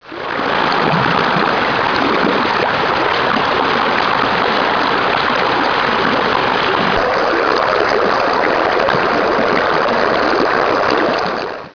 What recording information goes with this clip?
Colorado River Headwaters, River 1 One of the Colorado River's many appearances in the Grand Lake area.